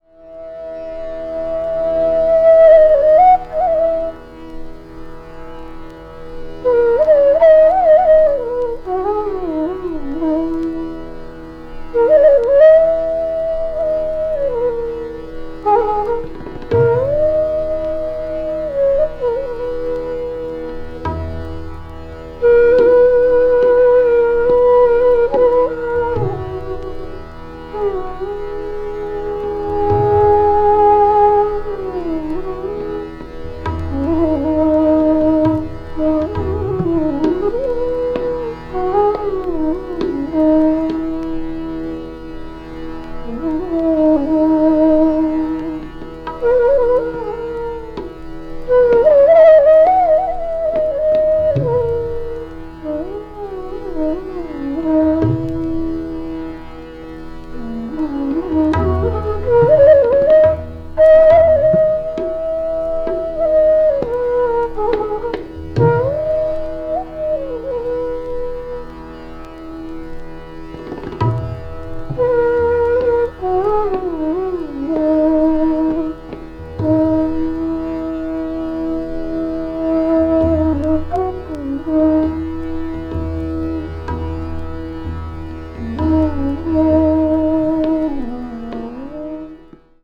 media : EX-/EX(わずかにチリノイズが入る箇所あり,A:再生音に影響ない薄いスリキズ1本あり)